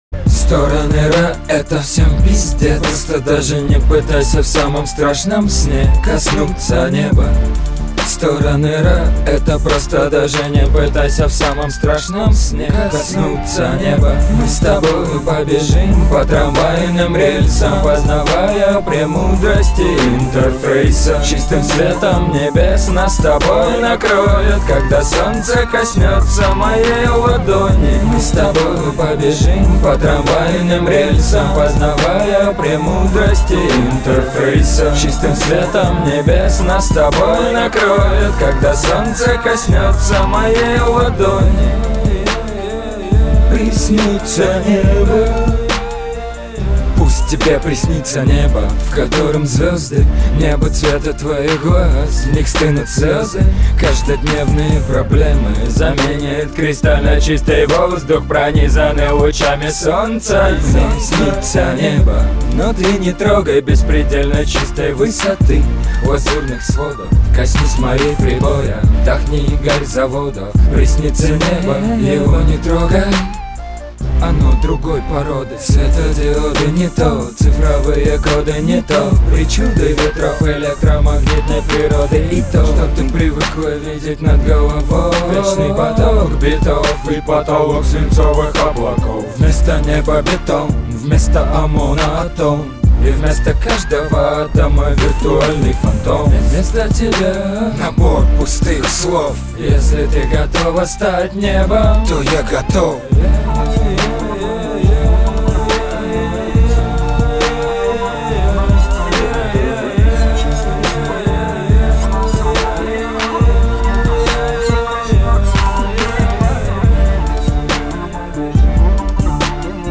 • Качество: 320, Stereo
Хип-хоп
качает